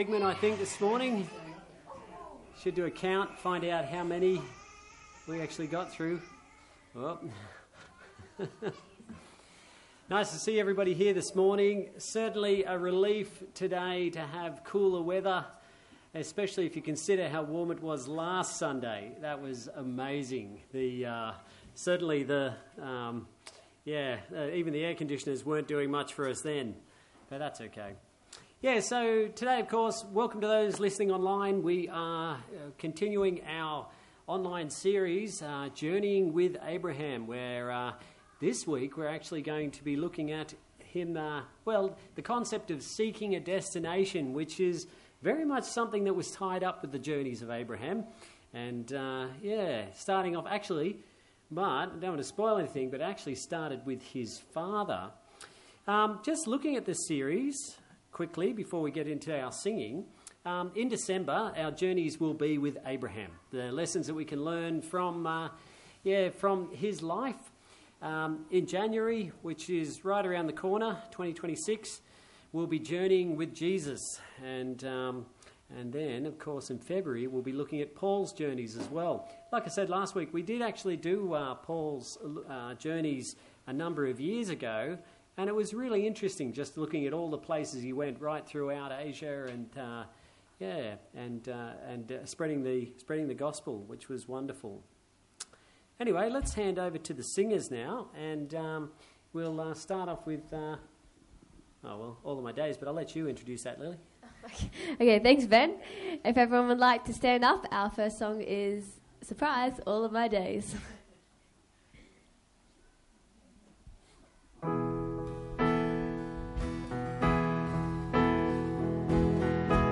Service Type: Sunday Church Abraham’s journey into the land of Canaan was a walk of faith marked by promise without possession.